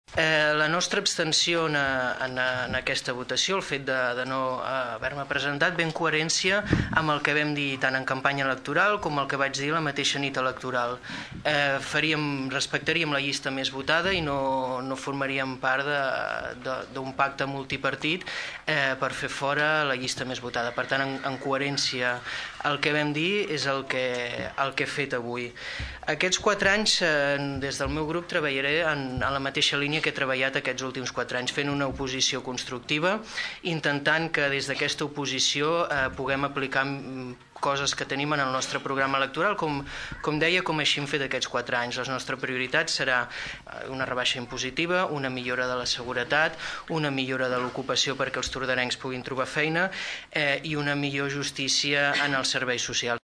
L’auditori del Teatre Clavé s’ha omplert de gom a gom durant en Ple de constitució del nou ajuntament.